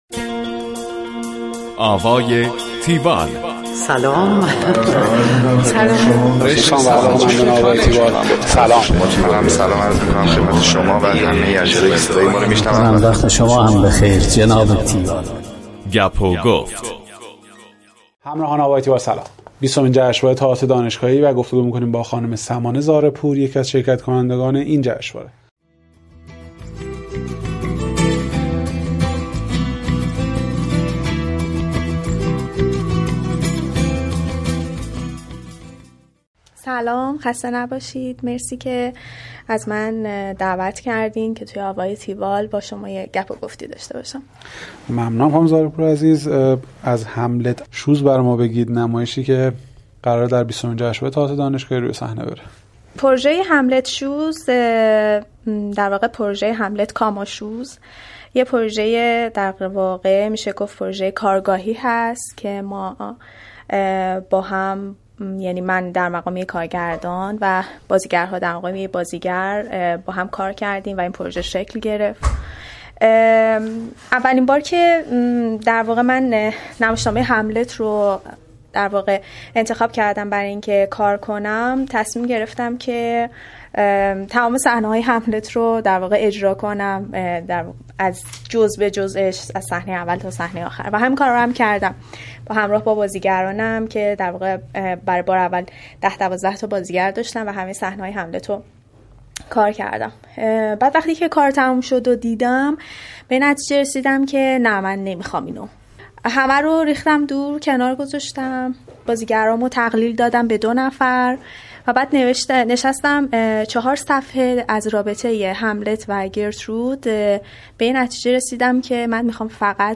نویسنده و کارگردان.